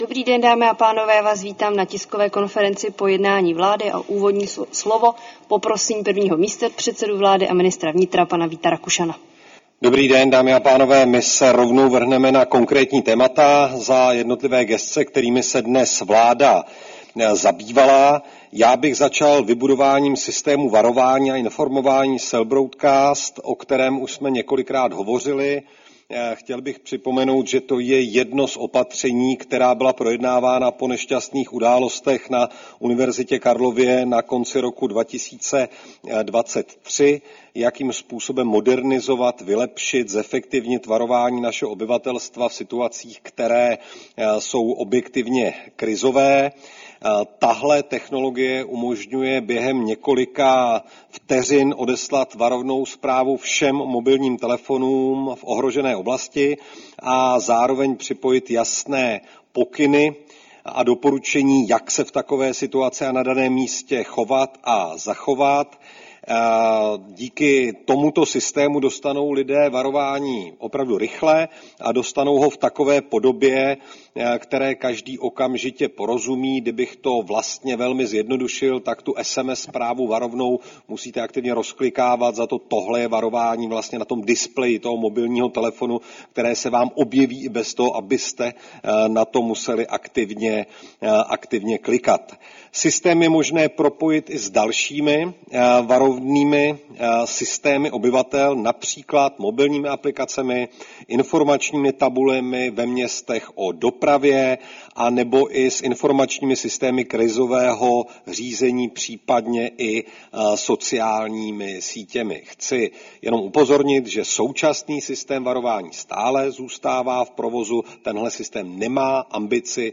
Tisková konference po jednání vlády, 21. května 2025